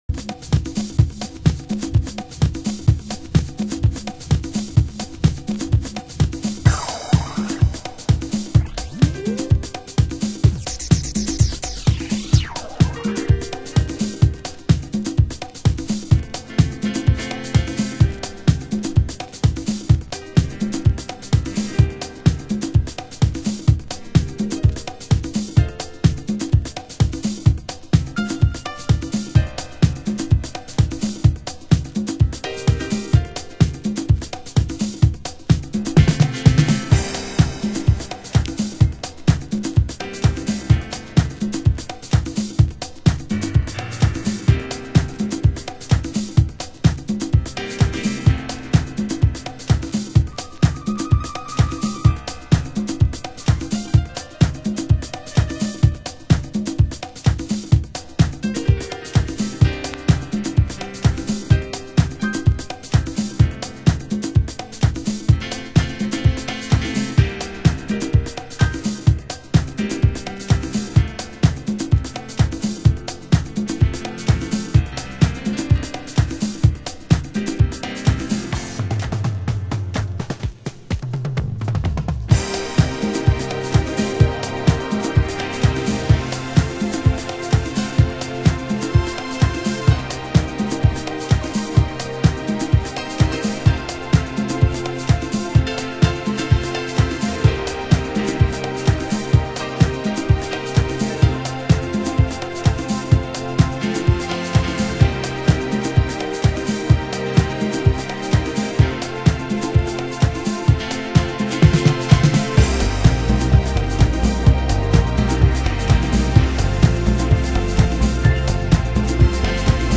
HOUSE MUSIC
中盤 　　盤質：少しチリパチノイズ有　　ジャケ：スレ有/破れ有